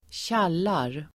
Uttal: [²tj'al:ar]